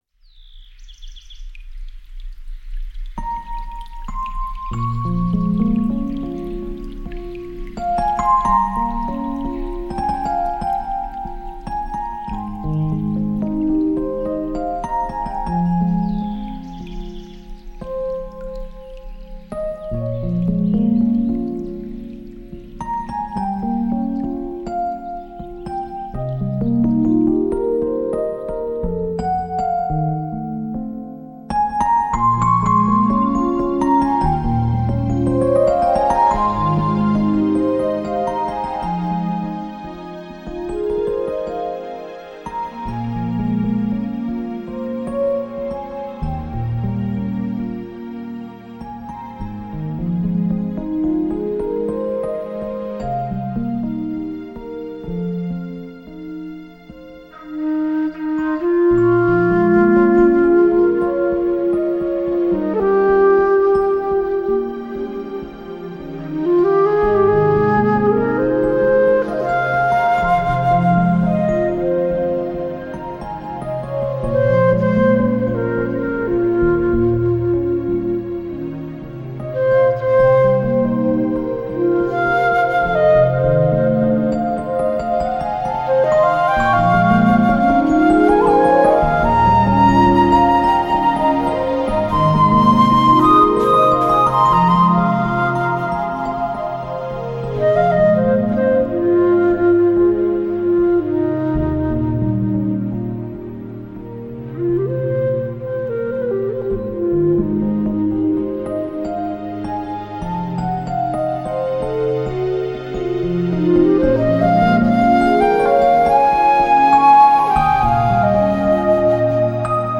舒缓而甜美的音乐
你都不妨让这舒缓而甜美的音乐将你包容！
为低音质MP3